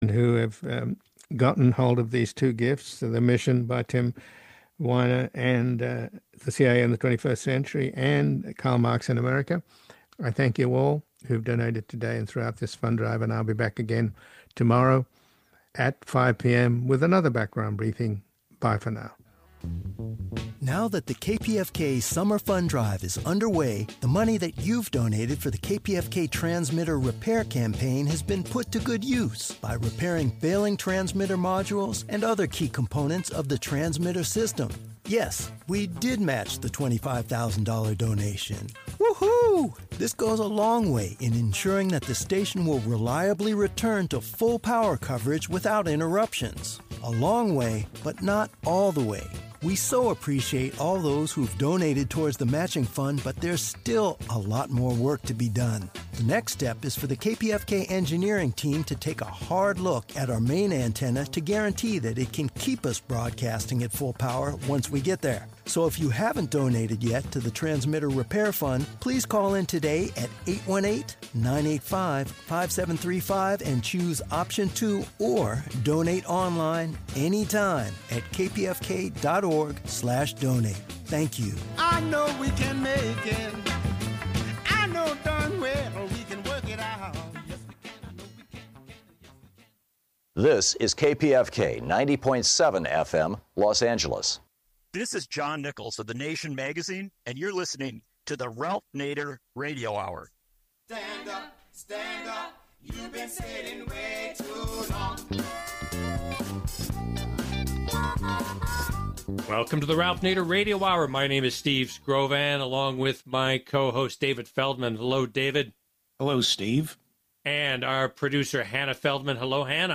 The Ralph Nader Radio Hour is a weekly talk show broadcast on the Pacifica Radio Network.